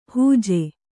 ♪ hūje